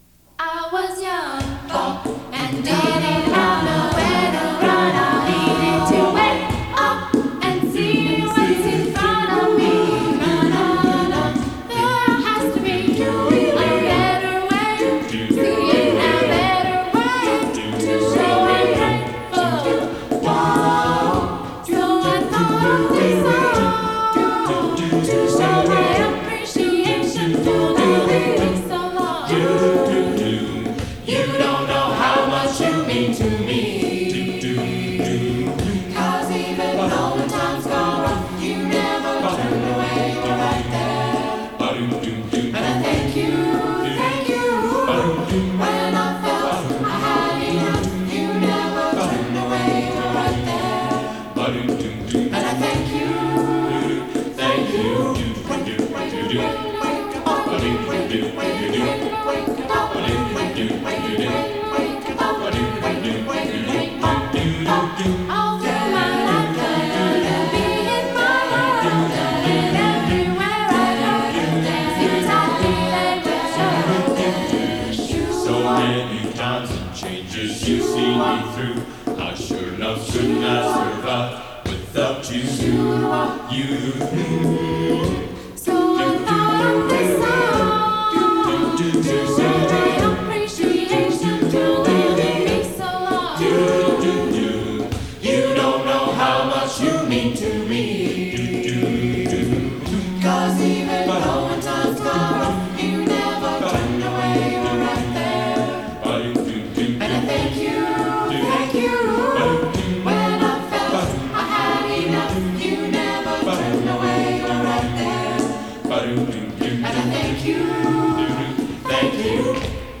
It's an acapella album!